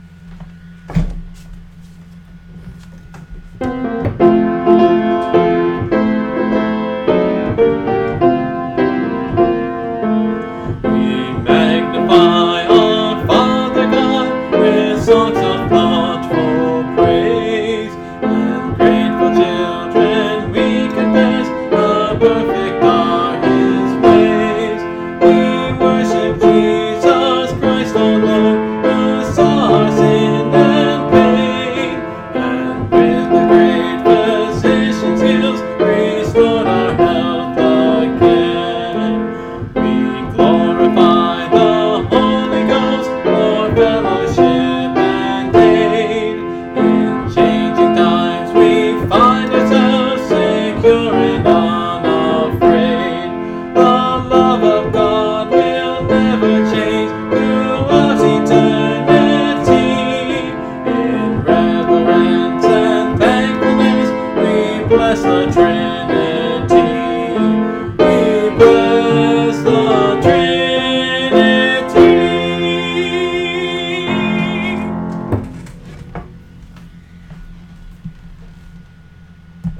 Part of a series singing through the hymnbook I grew up with